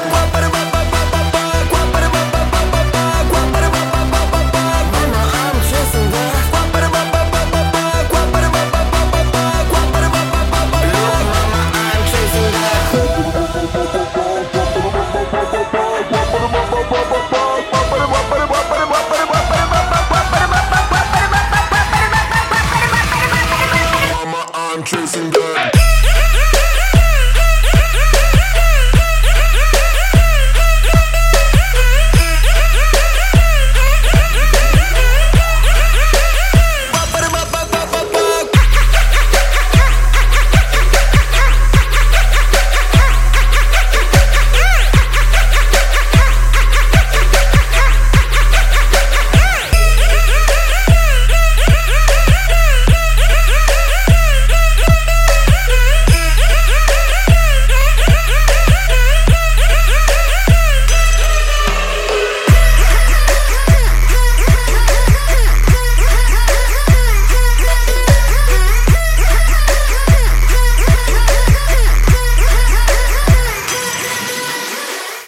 • Качество: 144, Stereo
Веселый прикольный звонок